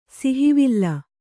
♪ sihivilla